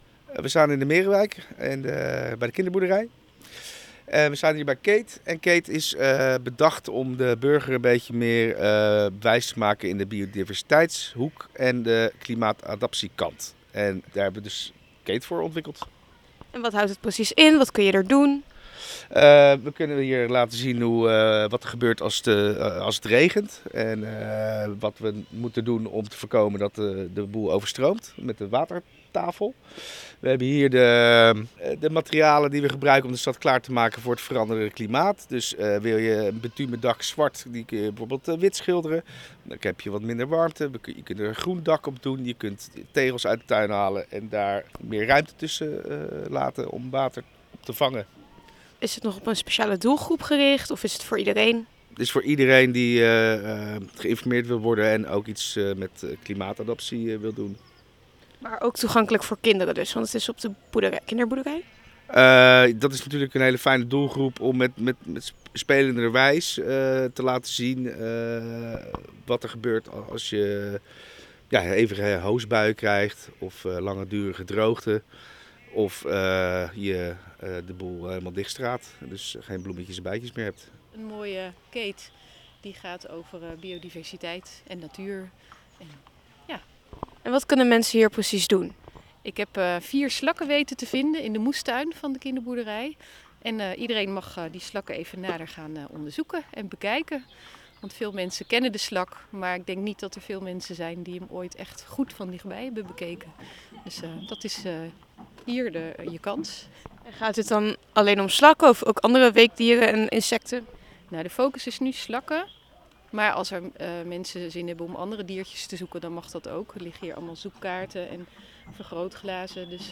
In deze omgebouwde bouwkeet ontdekken bewoners op toegankelijke wijze alles over biodiversiteit en klimaatadaptatie. Op woensdag 21 mei streek KEET neer bij de kinderboerderij in de Merenwijk, waar jong en oud worden geïnspireerd om hun eigen leefomgeving groener en klimaatbestendiger te maken.
gaat in gesprek met medewerkers van KEET